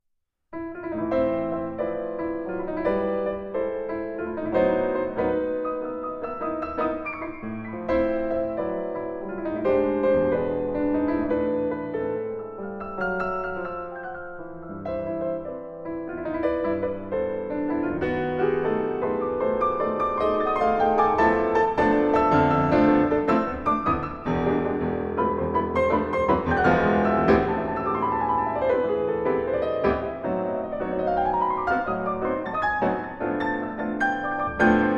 Classical Chamber Music Piano
Жанр: Классика